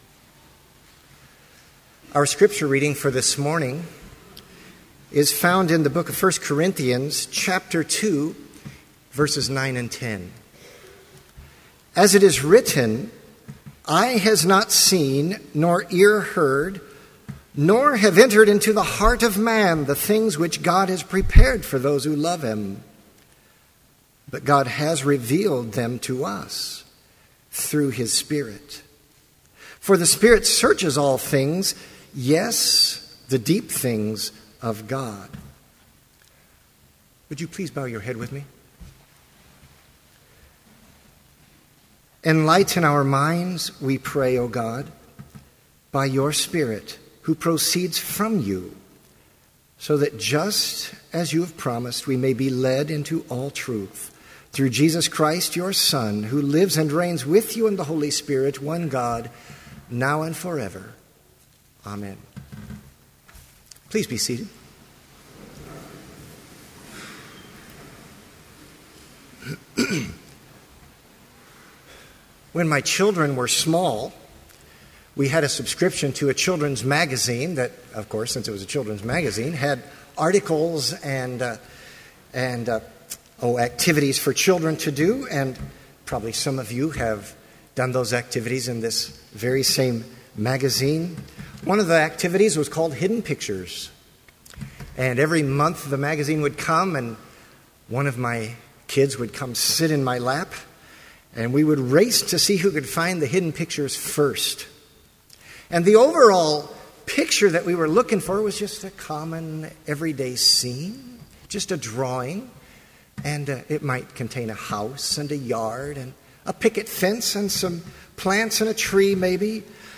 Complete service audio for Chapel - August 31, 2012